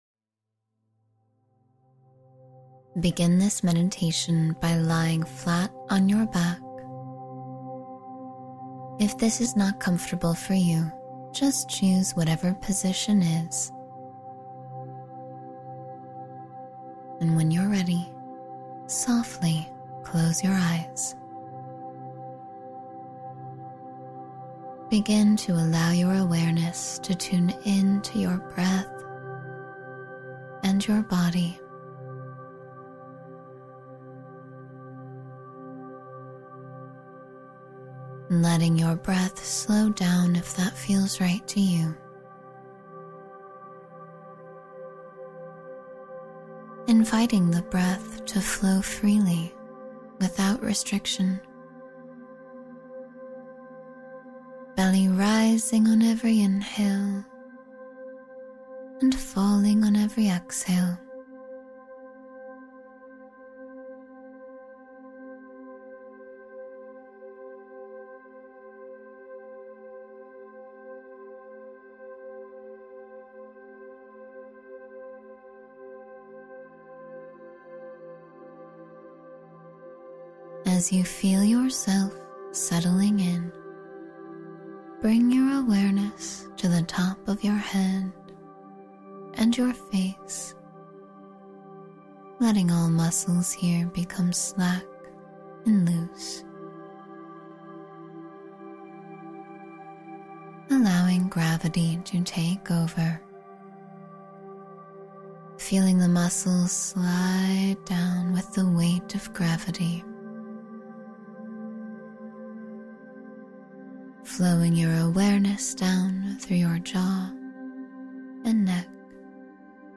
Love, Happiness, and Pure Positivity — Guided Meditation for Joyful Living